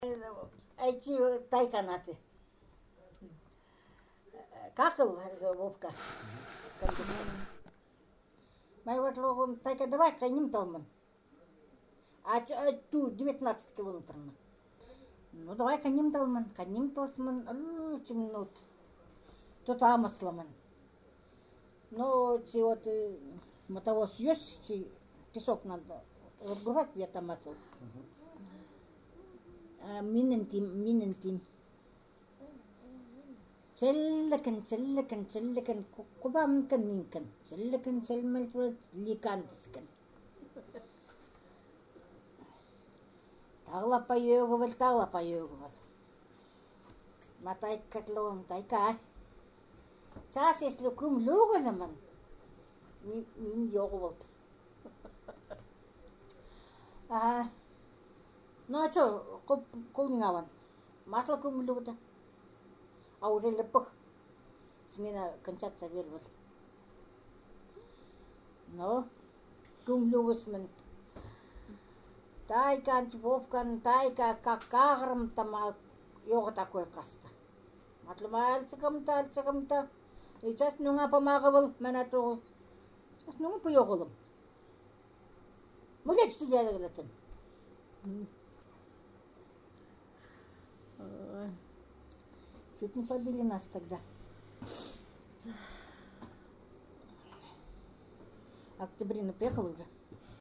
These Eastern Khanty texts were recorded in 2007 in the upper and lower Vasyugan river areas, and in the Alexandrovo Ob’ river communities. The texts were narrated by the male and female Vasyugan Khanty and Alexandrovo Khanty speakers to other Khanty speakers and to the researchers, who also spoke limited Khanty and offered occasional interjections to the narration.